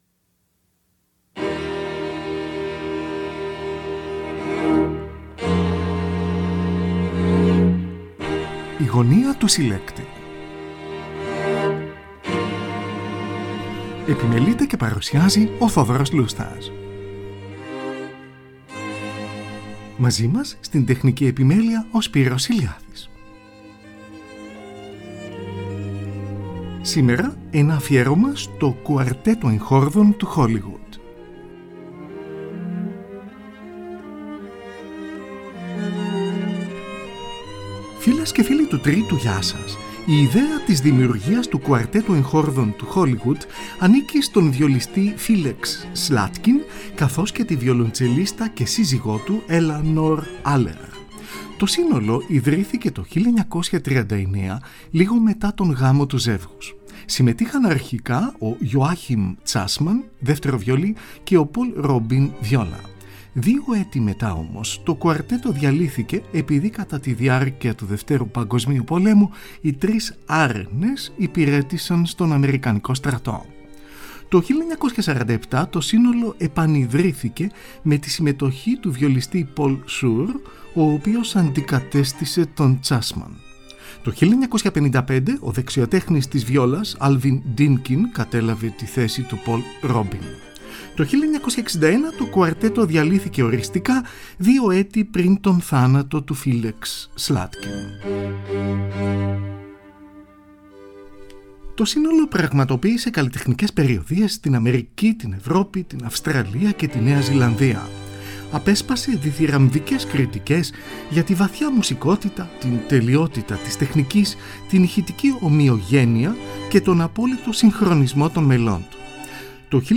πρώτο βιολί
δεύτερο βιολί
βιολοντσέλο. Aκούγονται κουαρτέτα των Franz Schubert και Ludwig van Beethoven .